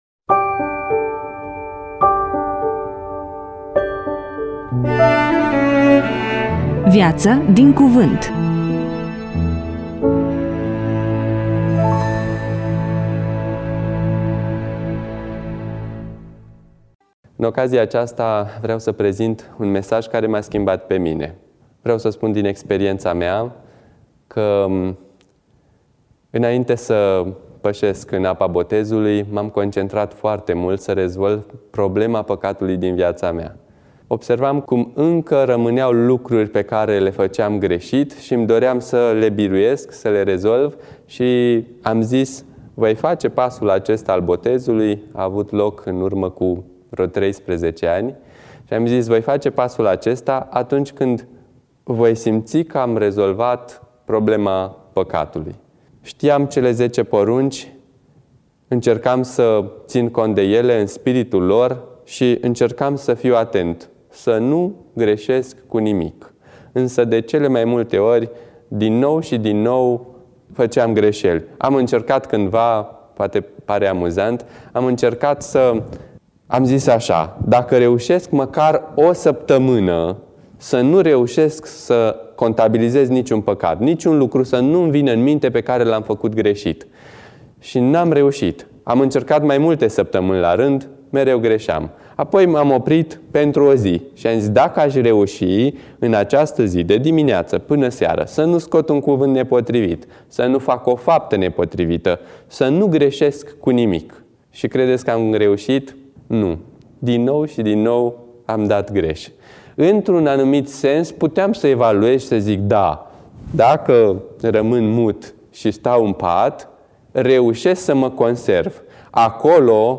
EMISIUNEA: Predică DATA INREGISTRARII: 03.08.2024 VIZUALIZARI: 402